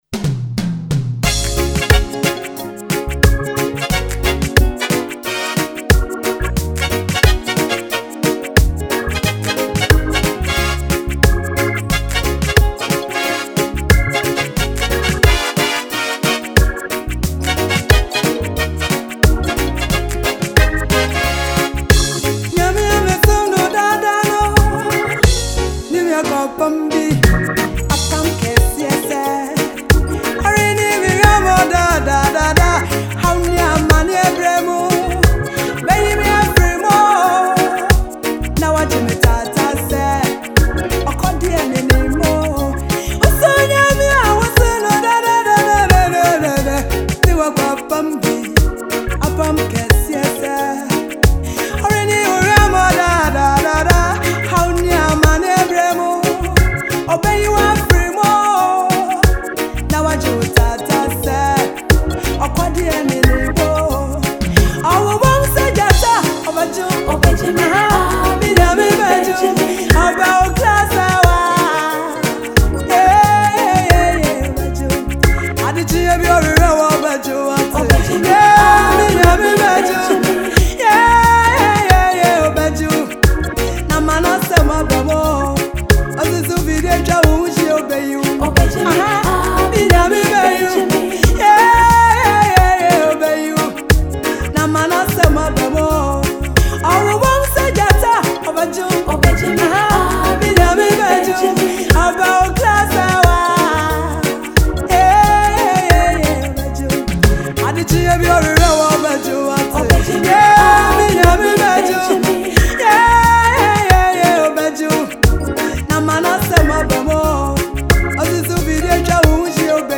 GHANA • MUSIC 🇬🇭GOSPEL • MUSIC
is a faith-inspiring gospel song